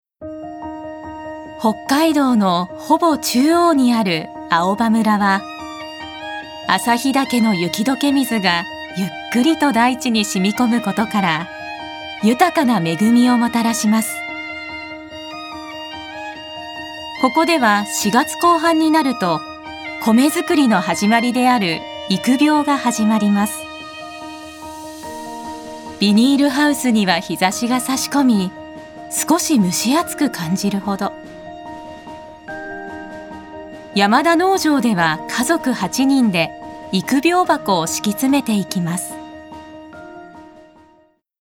Voice／メゾソプラノ
ボイスサンプル